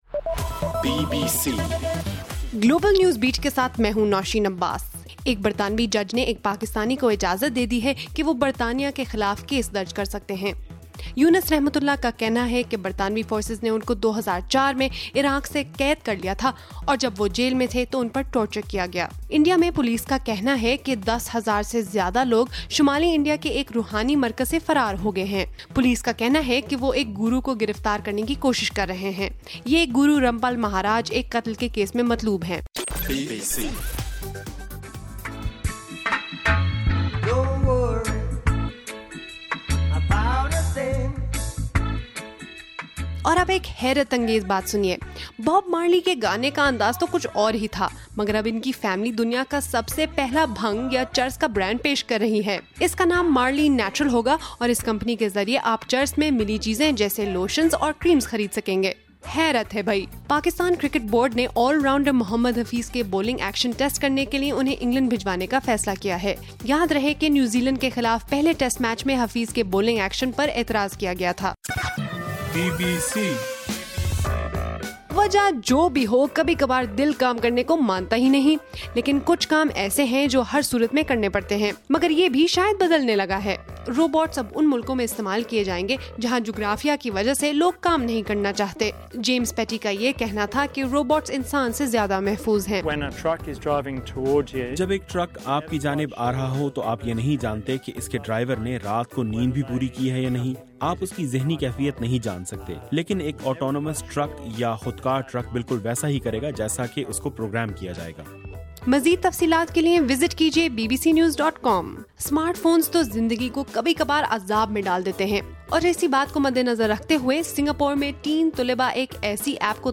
نومبر 19: رات 12 بجے کا گلوبل نیوز بیٹ بُلیٹن